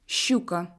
Deux consonnes sont toujours molles: Ч, Щ
Si elles sont suivies d'une voyelle de première série, la prononciation de la voyelle change sous l'influence de la consonne: on prononcera la voyelle de deuxième série correspondante.